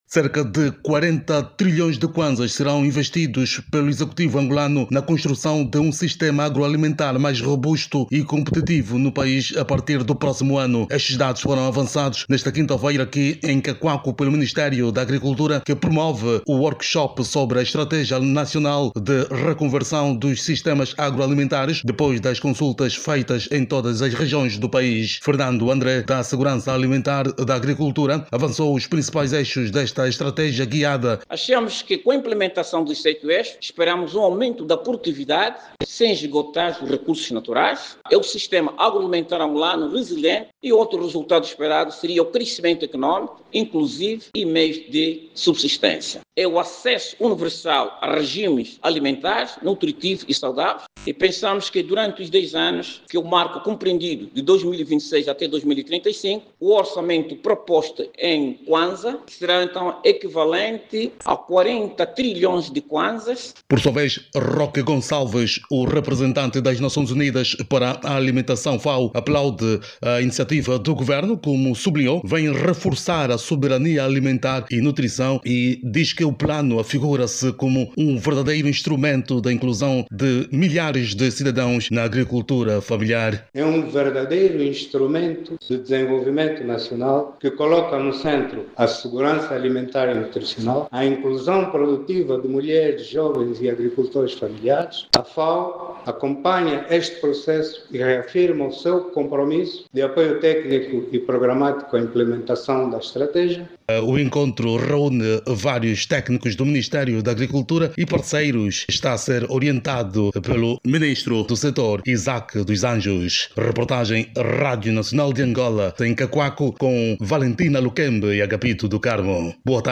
a partir de Cacuaco.